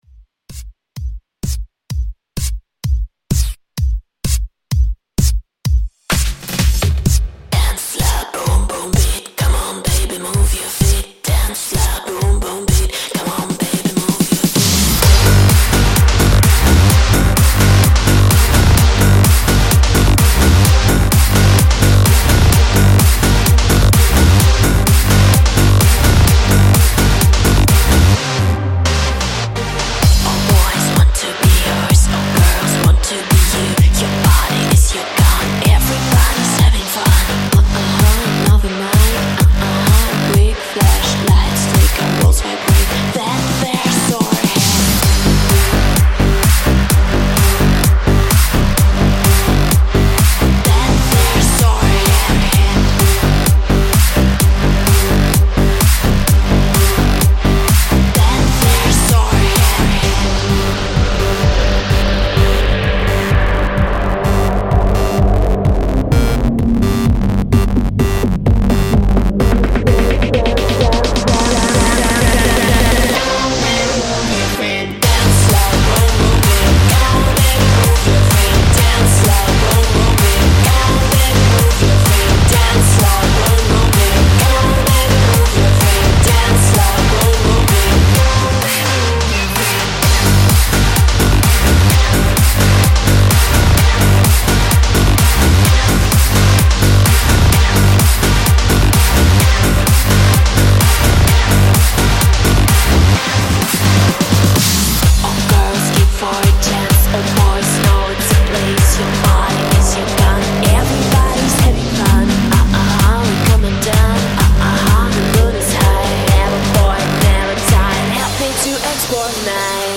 DARK POP